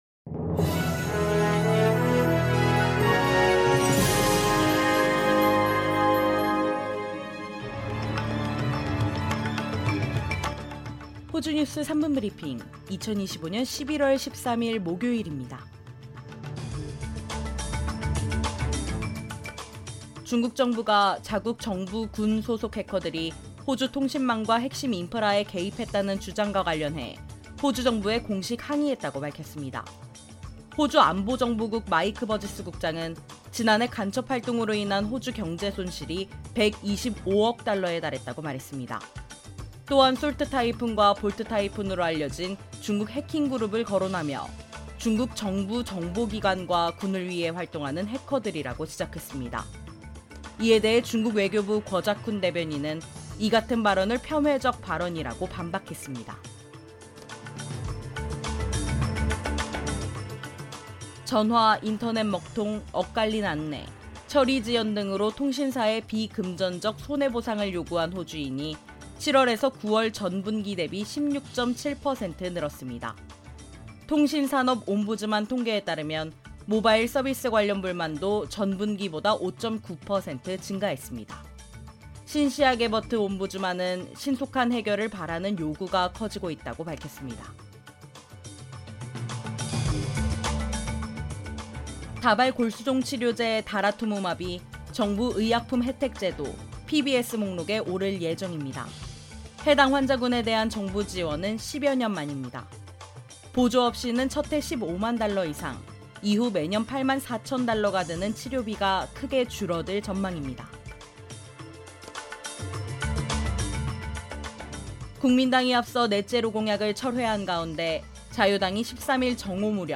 호주 뉴스 3분 브리핑: 2025년 11월 13일 목요일